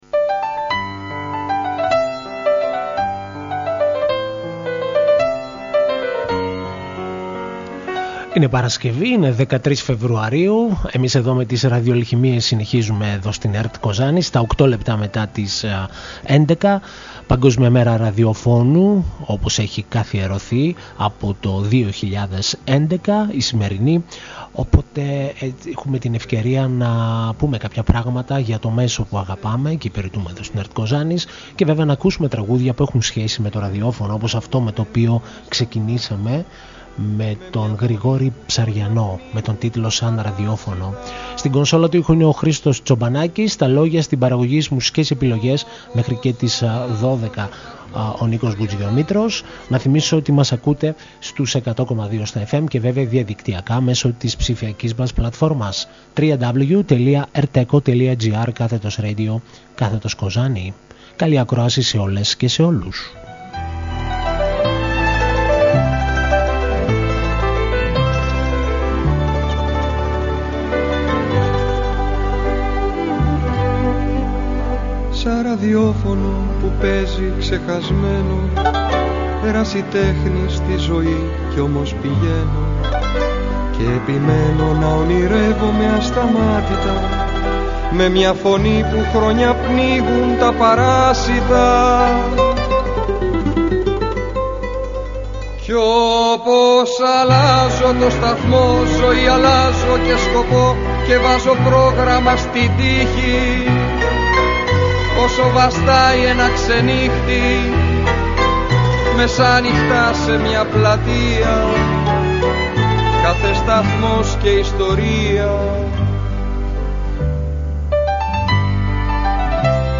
Η εκπομπή είναι διανθισμένη με τραγούδια από την Ελληνική δισκογραφία που έχουν σαν θέμα το αγαπημένο μας μέσο!
Μια εκπομπή μουσικής και λόγου διανθισμένη με επιλογές από την ελληνική δισκογραφία.